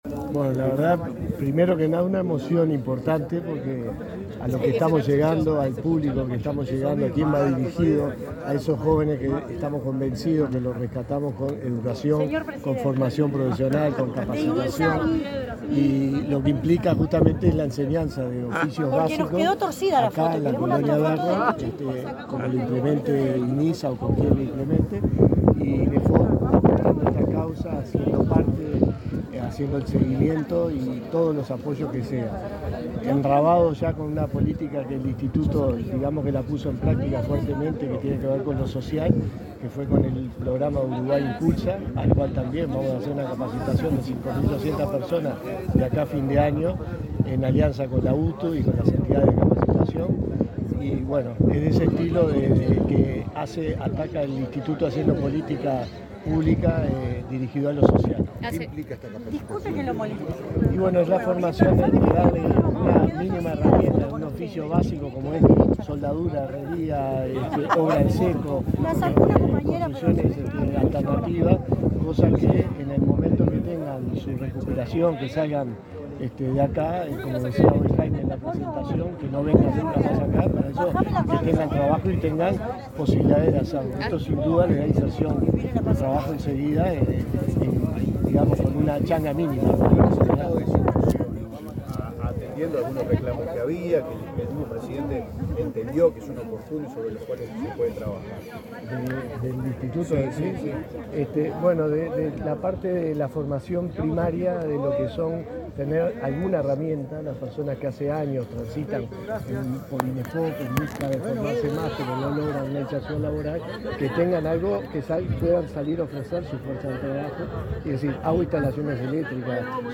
Declaraciones del director general de Inefop, Miguel Venturiello
Durante la firma de un convenio entre los institutos nacionales de Inclusión Social Adolescente y de Empleo y Formación Profesional, el director